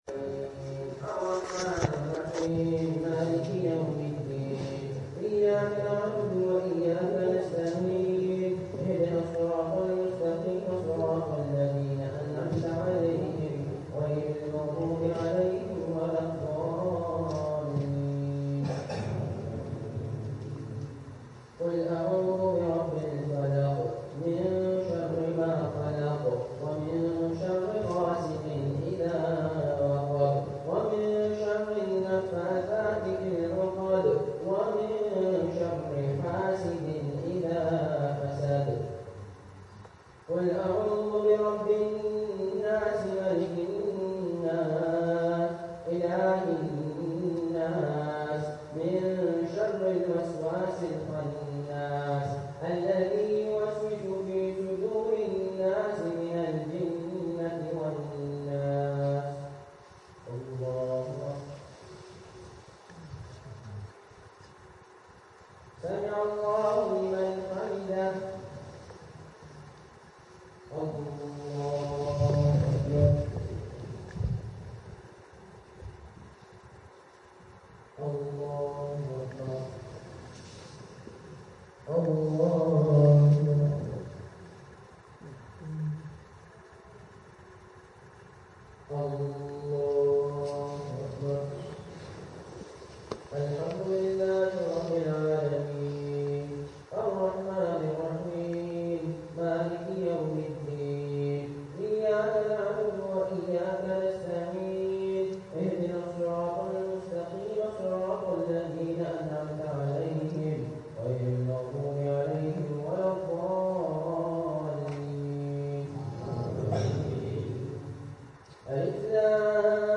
Night 11 – VIDEO – 10 Night Taraweeh – Khatam Al Qur’an and Dua – Bramalea Islamic Cultural Centre – BICC – 25 Kings Cross Road – Brampton, Ontario